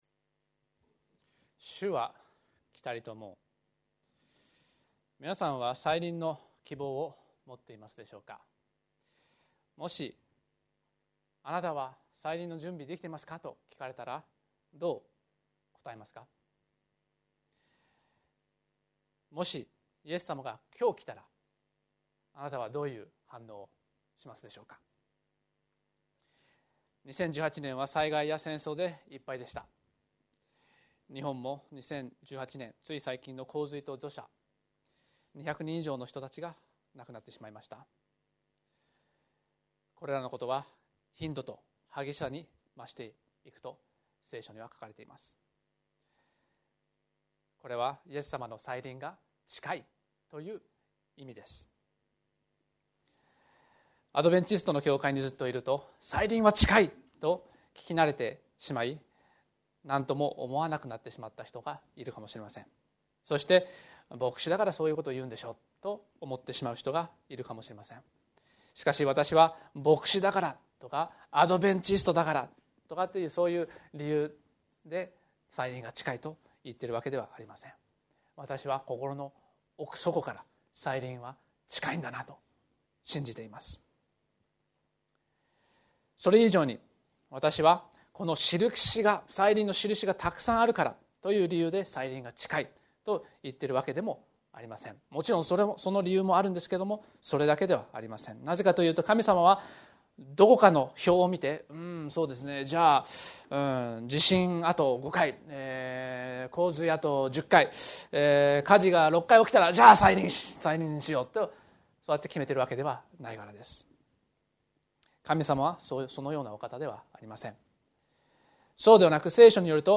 元旦礼拝 「新年の抱負」